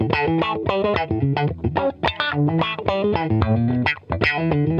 FUNK-E 4.wav